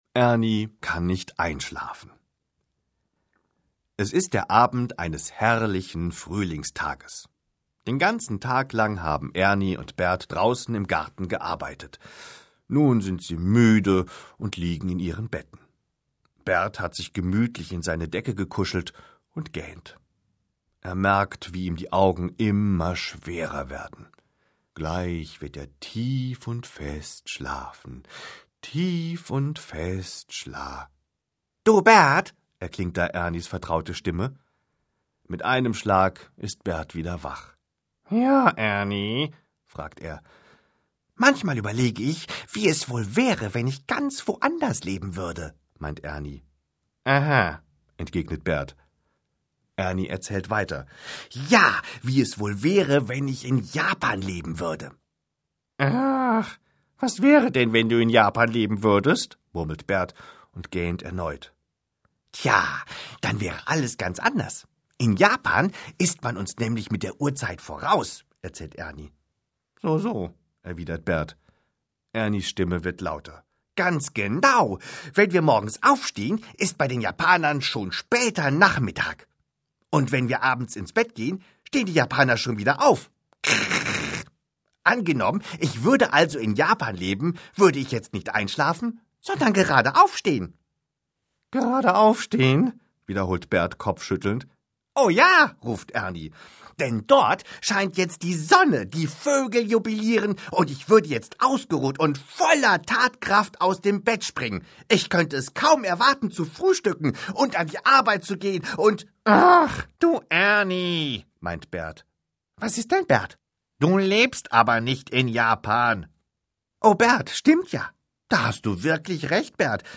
Schlagworte Hörbuch • Hörbuch; Lesung für Kinder/Jugendliche • Kinder • Kinder & Jugend • Kinder & Jugend • Kinder & Jugend, Hörbuch, Lesung • Kinder & Jugend, Hörbuch, Lesung, Kinder • Lesung